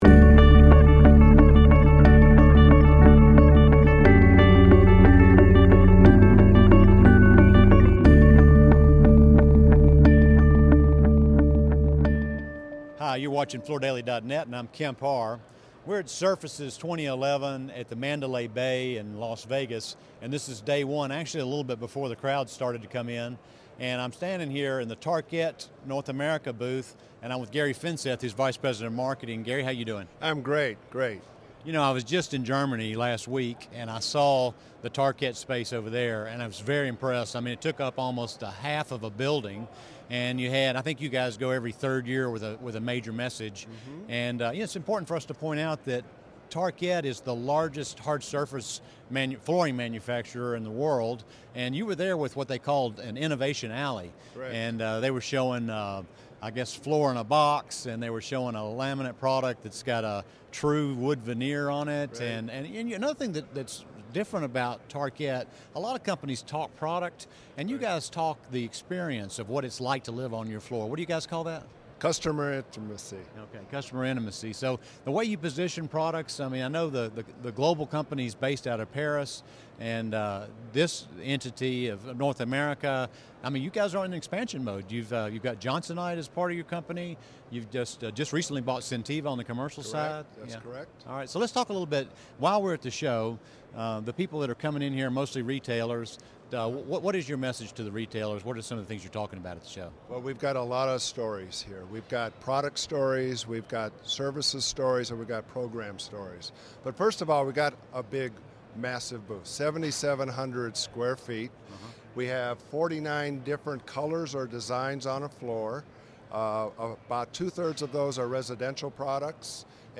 Recorded live from Surfaces 2011